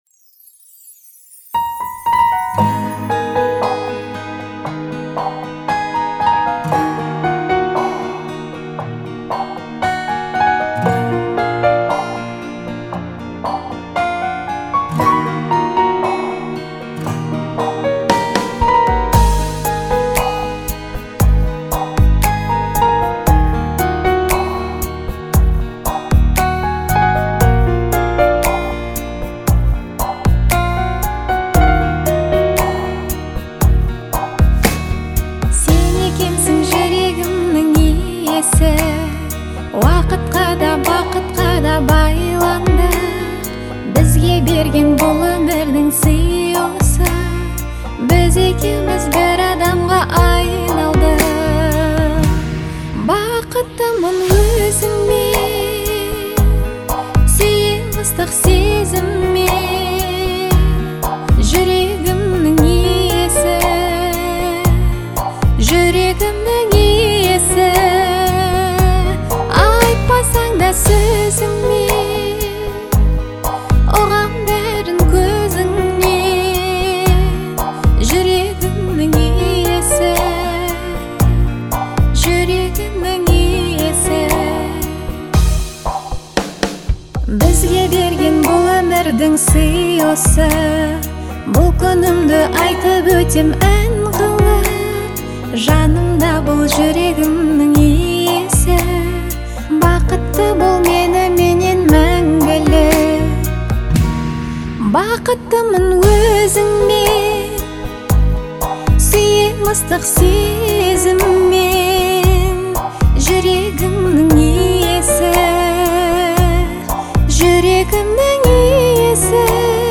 это эмоциональный трек в жанре поп